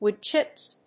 /ˈwʊˌdtʃɪps(米国英語)/